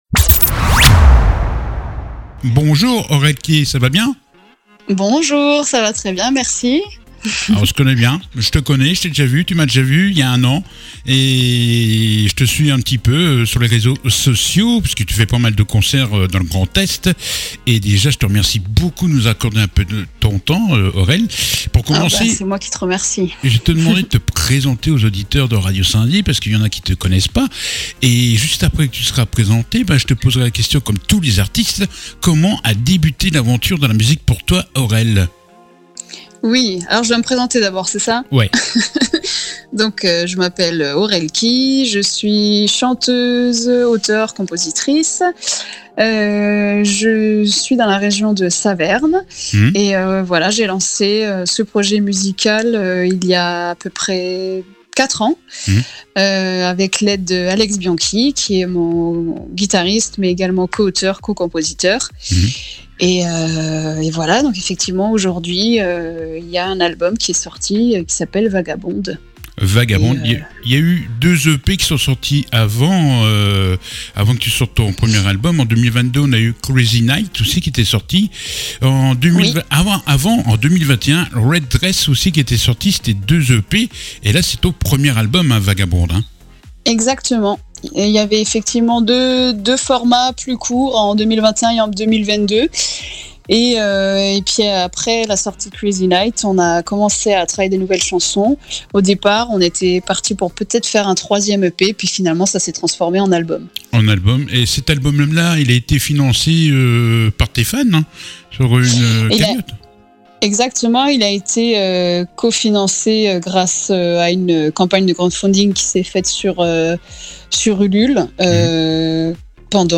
L'interview du jour